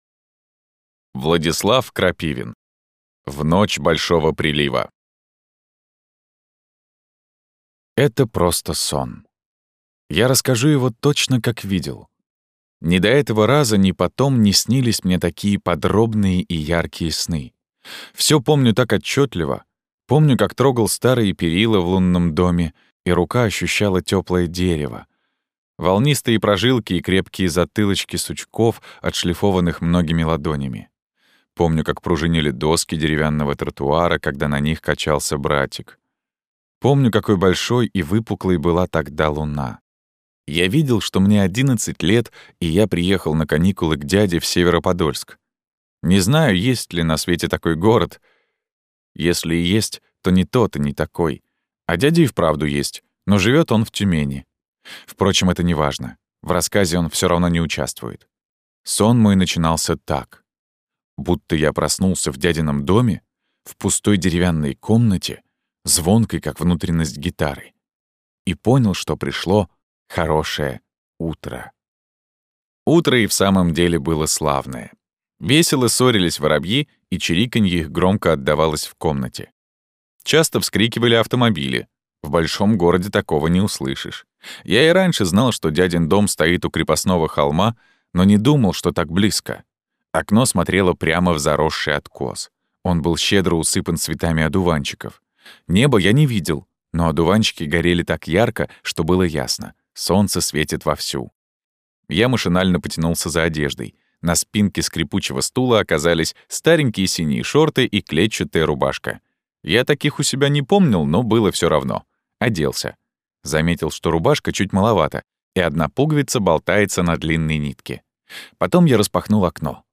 Аудиокнига В ночь большого прилива. Трилогия | Библиотека аудиокниг